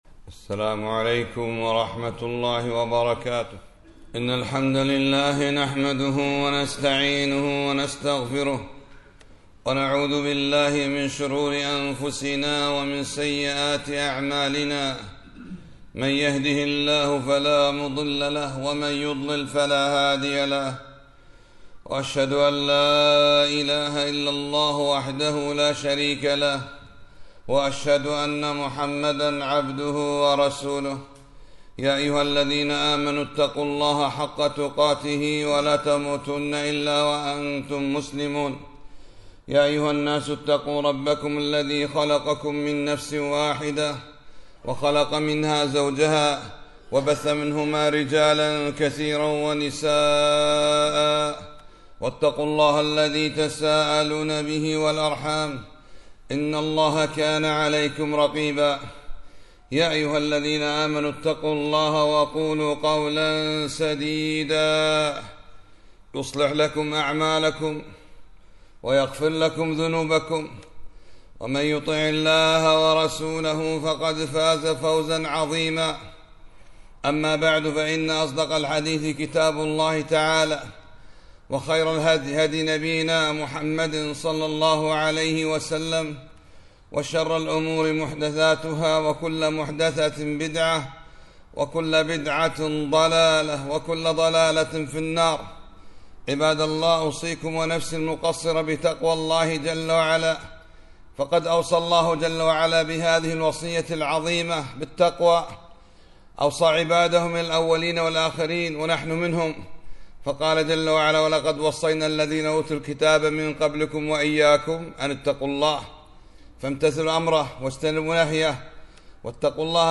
خطبة - خطورة اللسان على دين الإنسان